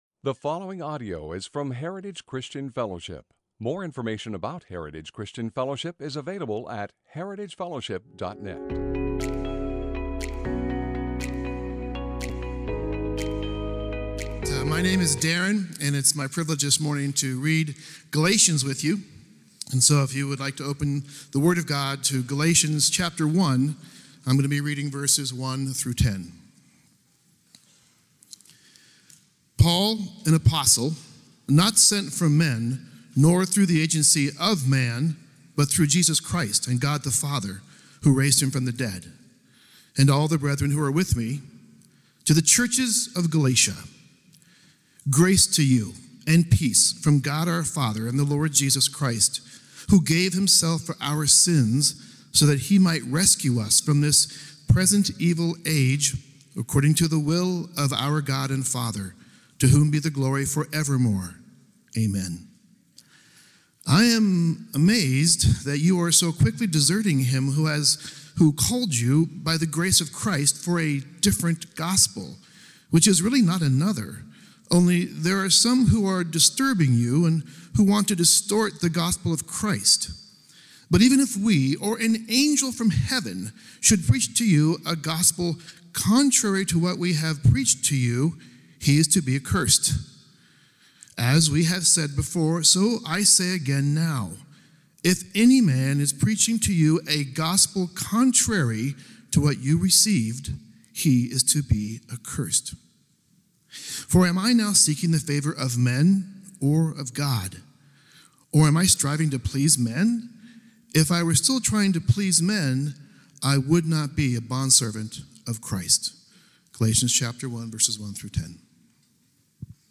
Sermons - Heritage Christian Fellowship | Of Medford, OR